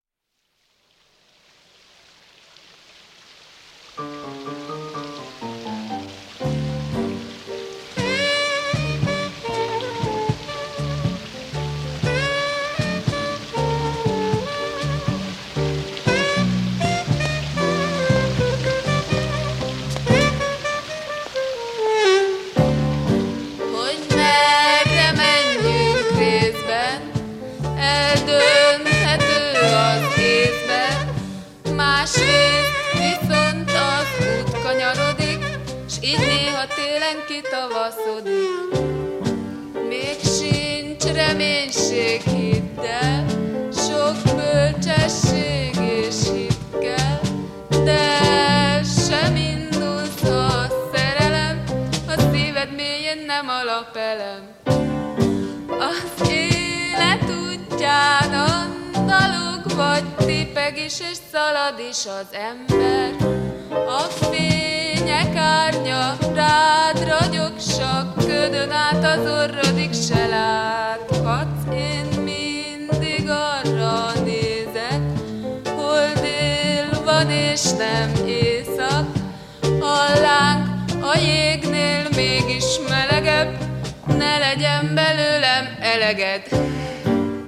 un groupe underground, d'avant-garde